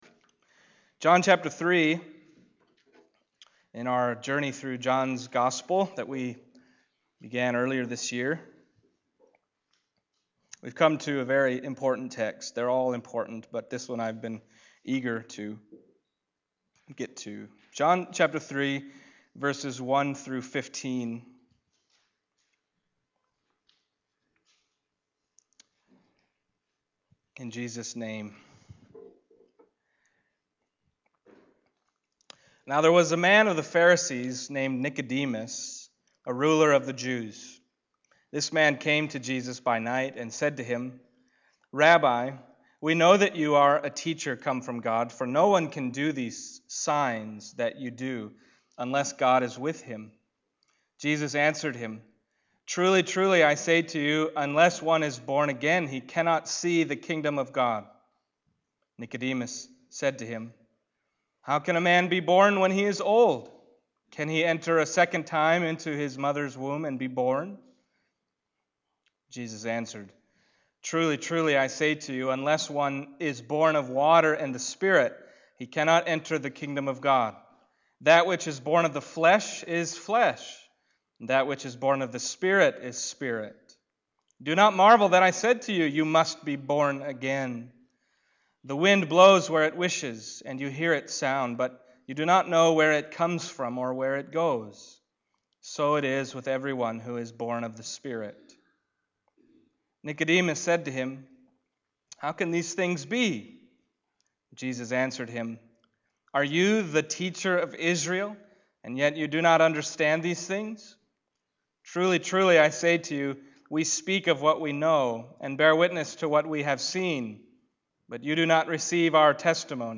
John Passage: John 3:1-15 Service Type: Sunday Morning John 3:1-15 « Jesus Is Where We Meet God You Must Be Born Again